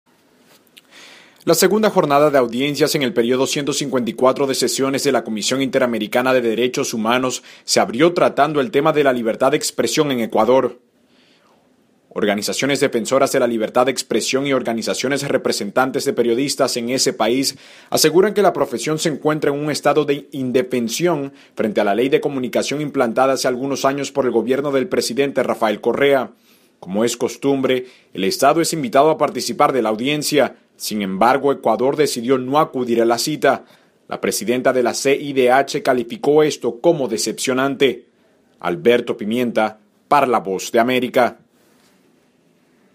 Nota Radio Audiencia Ecuador CIDH.